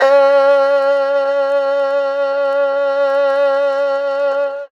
52-bi02-erhu-f-c#3.aif